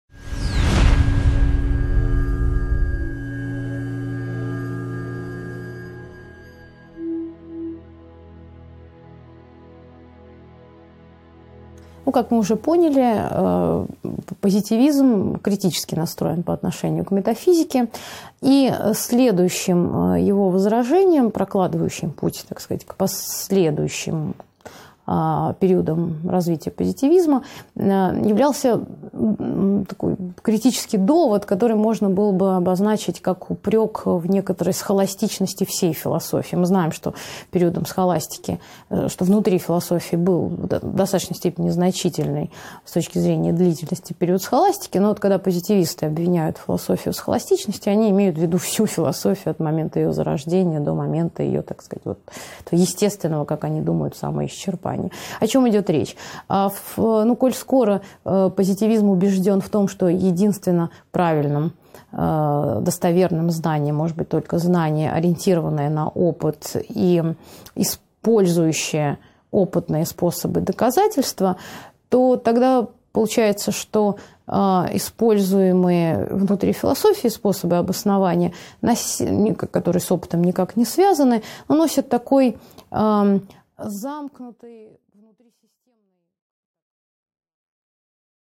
Аудиокнига 14.2 Верификация и фальсификация | Библиотека аудиокниг